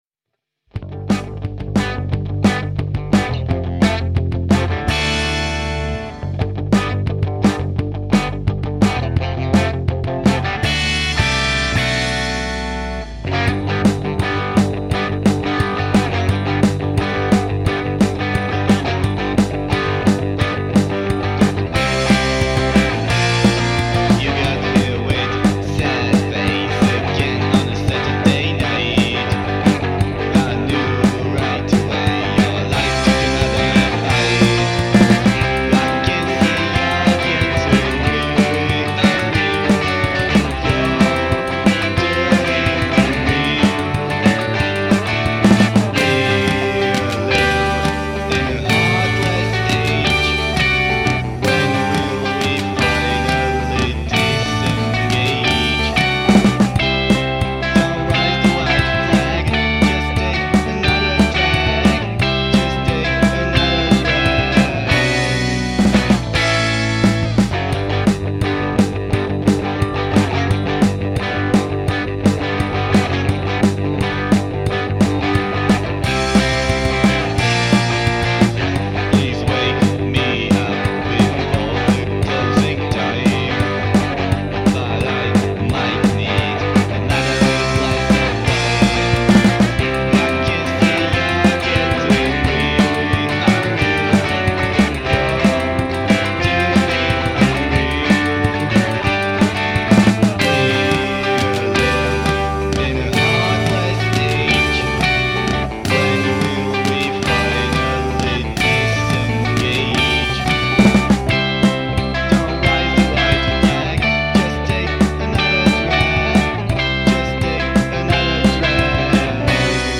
Žánr: Punk
Power pop/punk, Humenné, Slovensko.